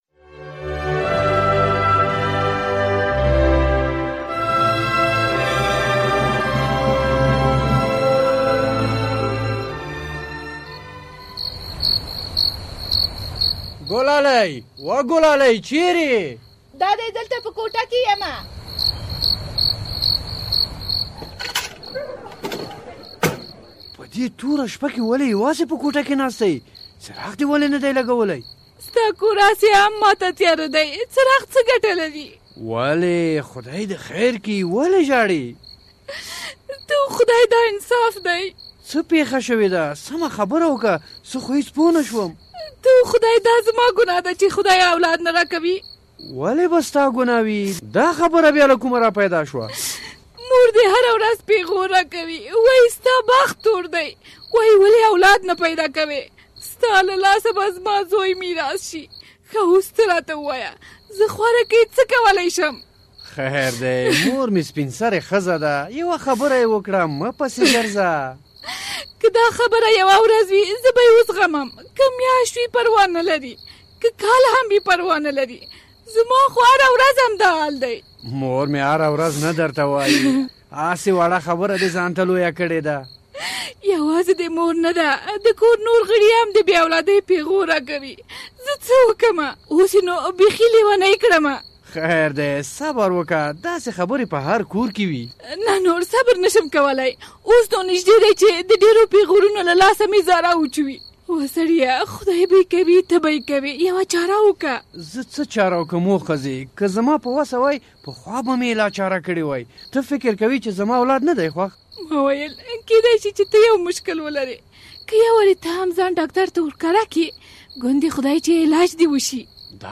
ډرامه